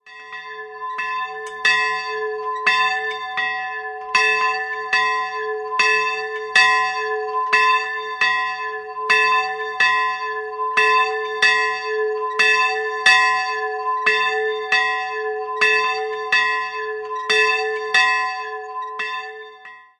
Westenhausen, Friedhofsglocke
Der Glockenträger auf dem Westenhausener Friedhof beinhaltet eine sehr wertvolle Glocke, die vermutlich noch aus dem 14. Jahrhundert stammt. Einzelglocke a''
Westenhausen_Friedhof.mp3